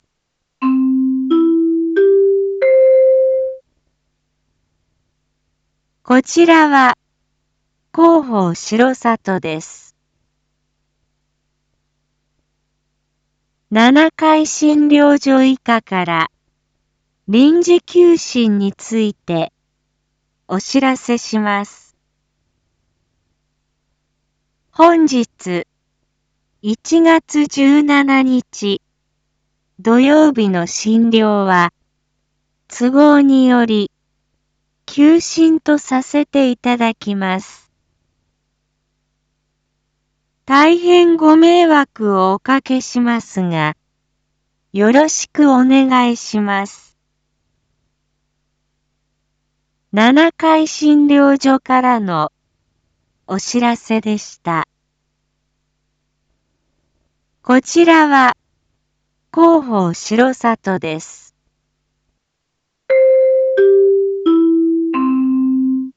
Back Home 一般放送情報 音声放送 再生 一般放送情報 登録日時：2026-01-17 07:01:07 タイトル：R8.1.17七会診療所臨時休診② インフォメーション：こちらは広報しろさとです。